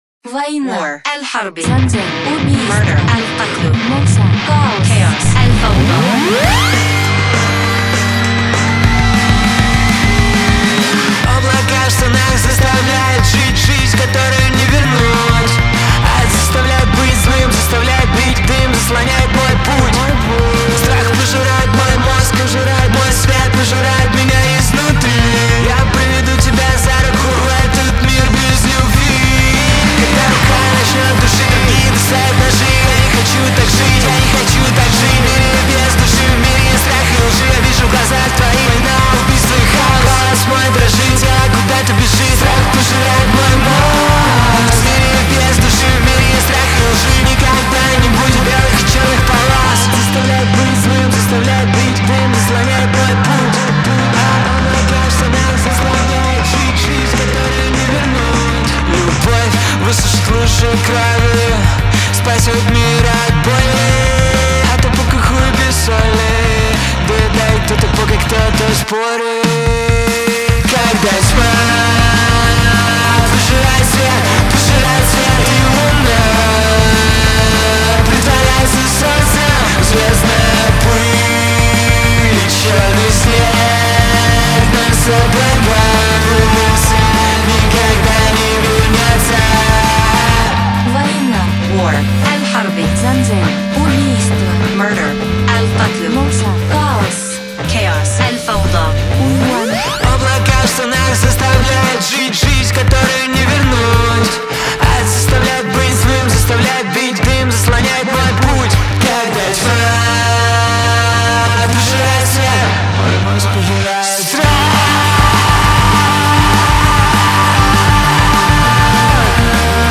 Категории: Русские песни, Альтернатива.